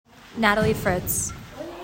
AUDIO PRONUNCIATION